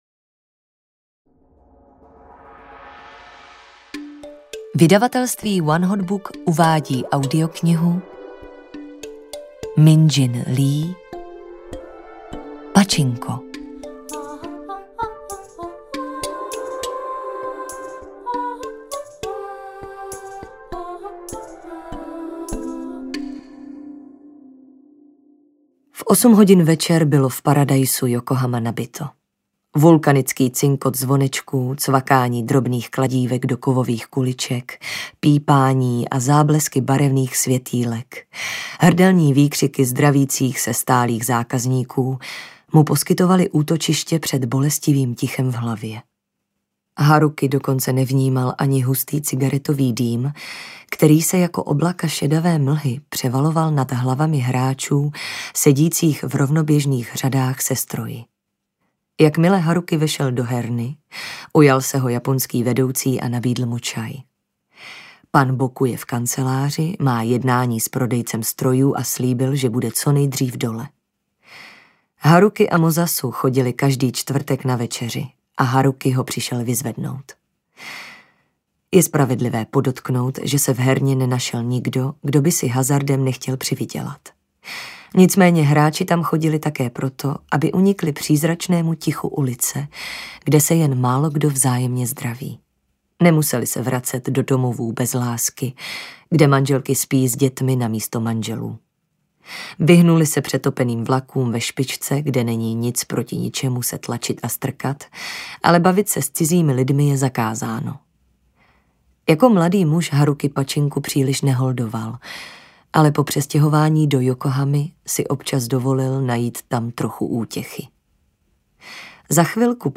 Pačinko audiokniha
Ukázka z knihy